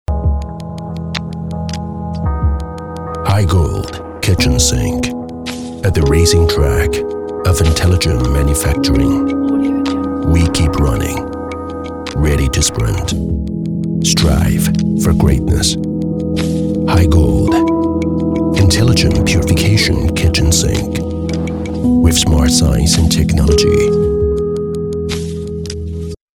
【广告】美式 广告3 中年音色
【广告】美式 广告3 中年音色.mp3